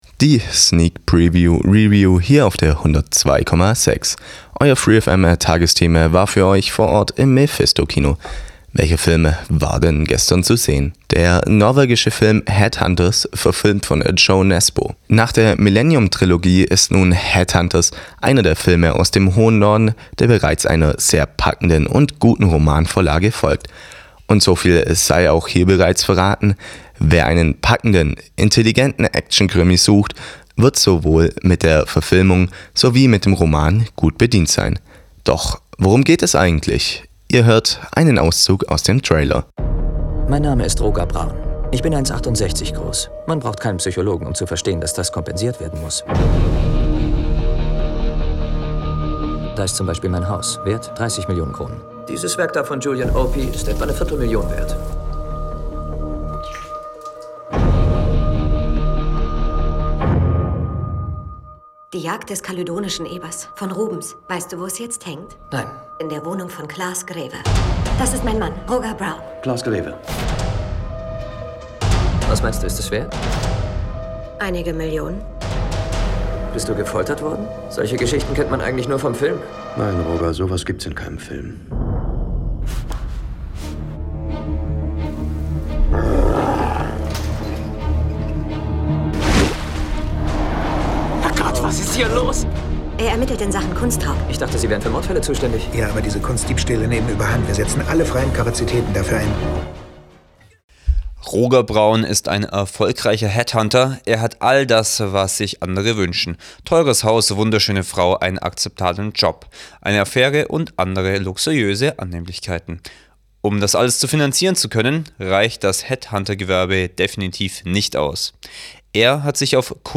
Die Sneak Preview Review hier auf der 102,6
Euer freefm Tagesteam war für euch vor Ort im Mephisto Kino